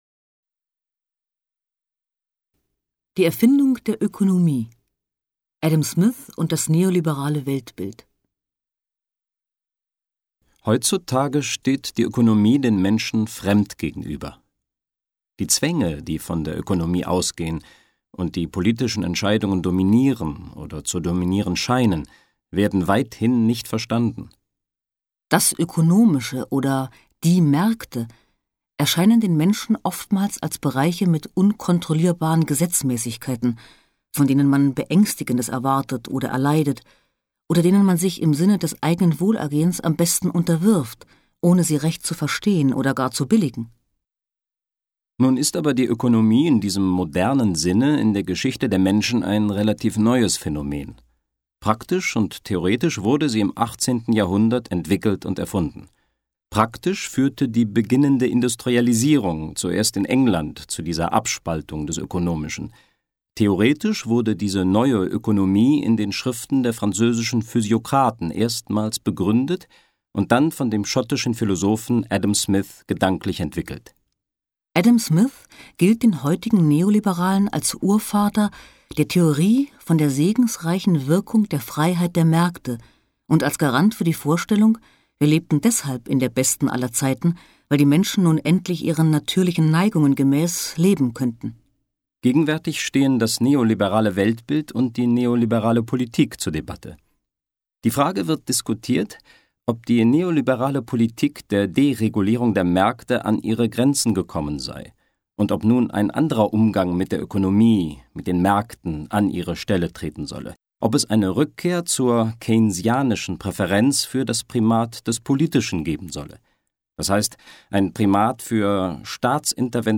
Das Hörbuch erzählt die spannende Geschichte der Ökonomie von der Antike bis zur heutigen Zeit.